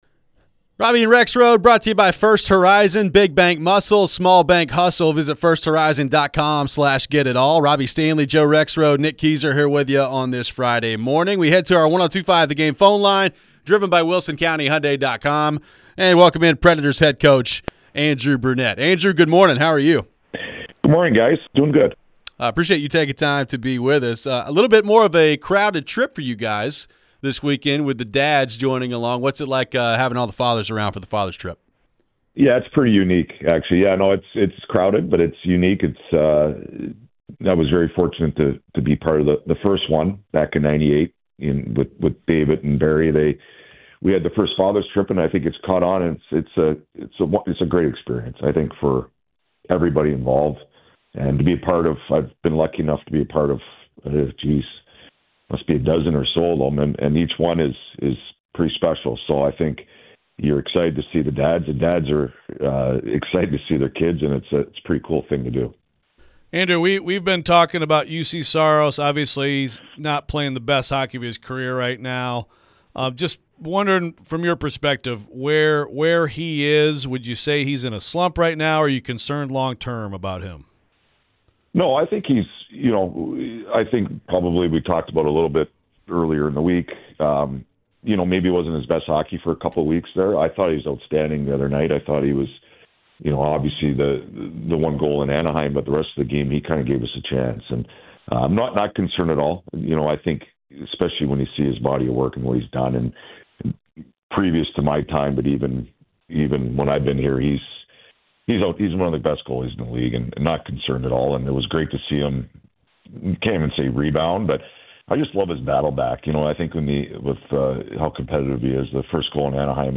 Andrew Brunette Interview (1-31-25)